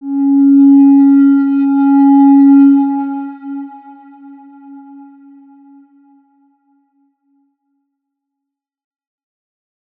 X_Windwistle-C#3-mf.wav